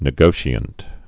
(nĭ-gōshē-ənt, -shənt)